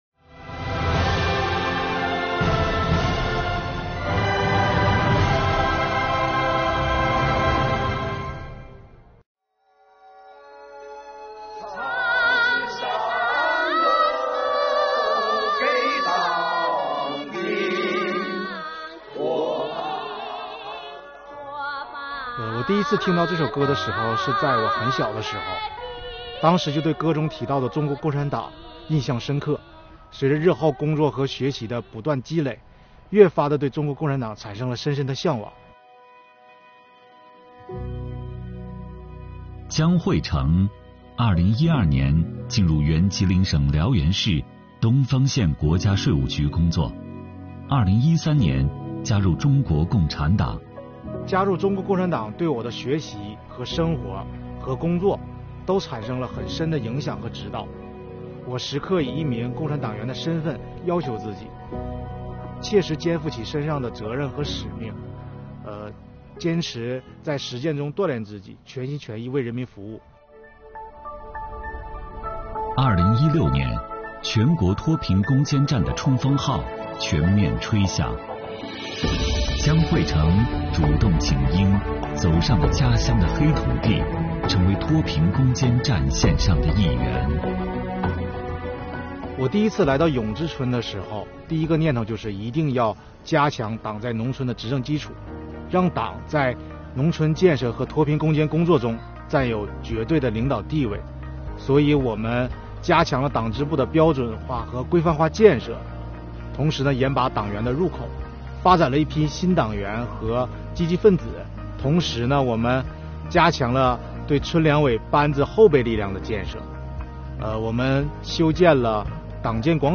为深入学习贯彻习近平总书记在党史学习教育动员大会上的重要讲话精神，按照税务总局党委统一部署，我们组织推出“百名党员讲税收故事”短视频宣传活动。通过讲好党的故事，讲好税收故事，激发税务系统广大党员干部奋斗“十四五”、奋进新征程的磅礴力量。